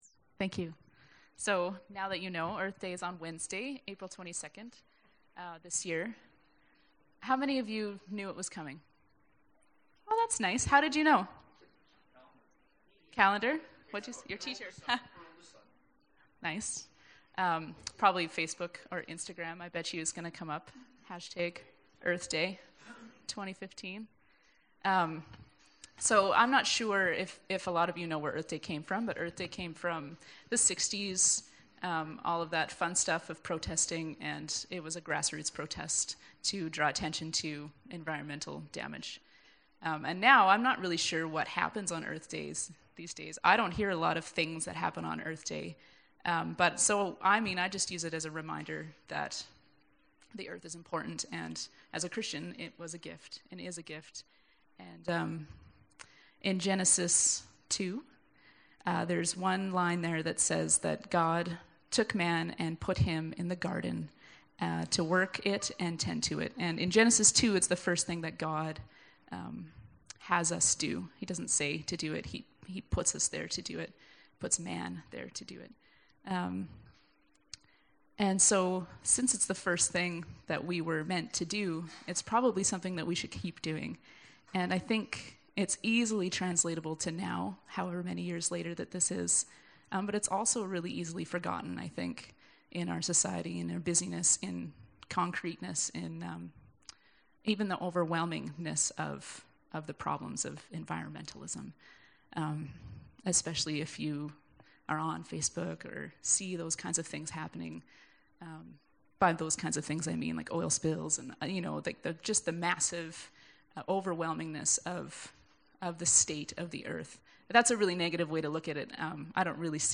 Service Type: Downstairs Gathering